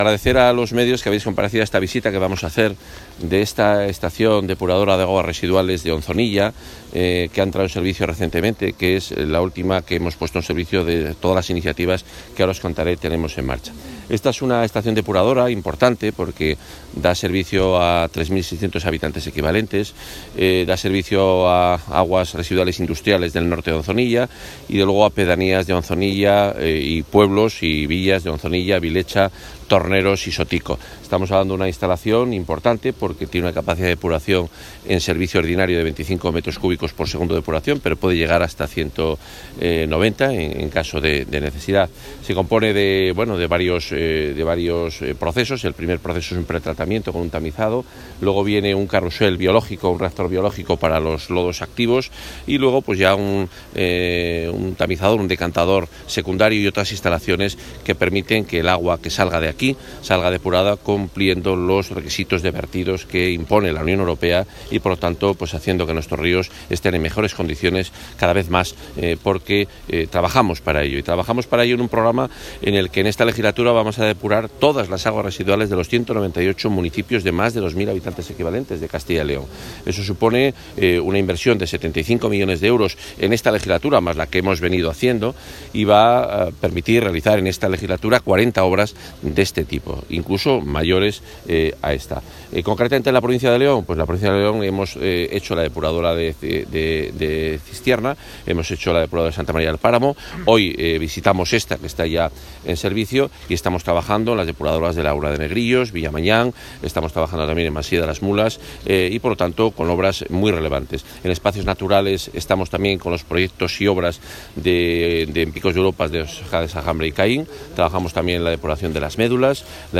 Consejero de Fomento y Medio Ambiente.